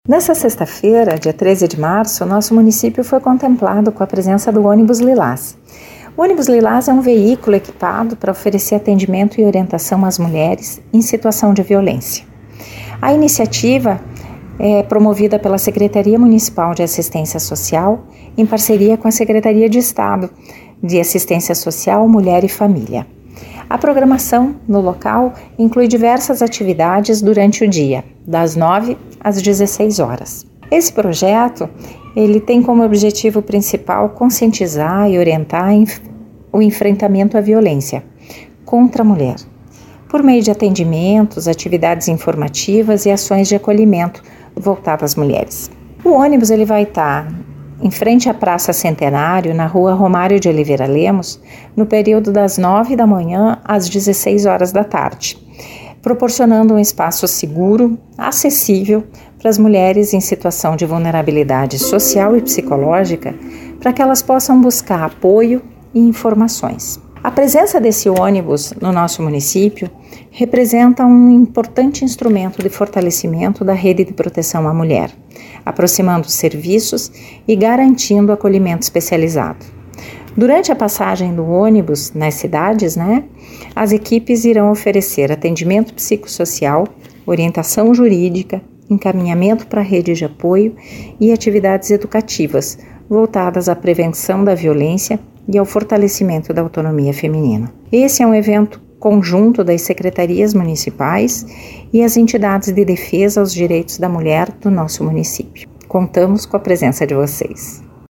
Secretária municipal de Assistência Social de Curitibanos, Ana Paula DellaGiustina falou sobre a atividade voltada a todas as mulheres, mas também a população em geral.